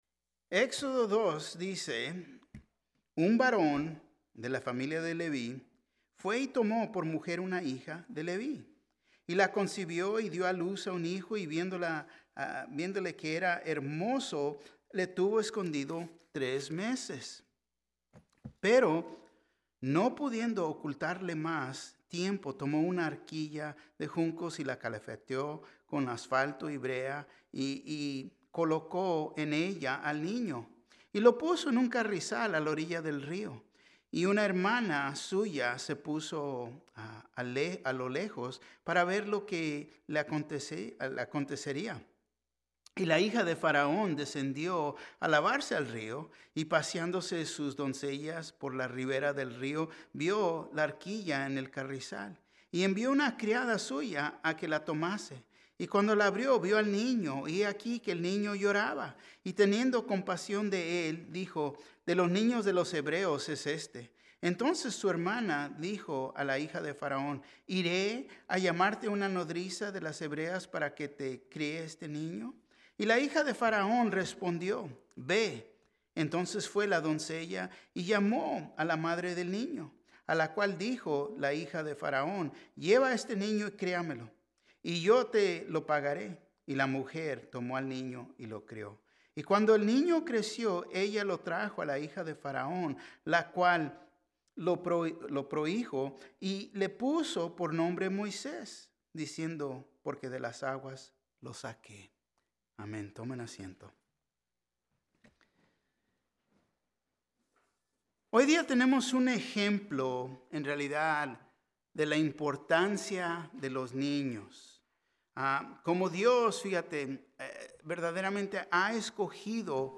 Un mensaje de la serie "Liberados." Los sufrimientos nos ayudan a buscar al Señor, producen paciencia y, a pesar de los sufrimientos, es mejor obedecer al Señor.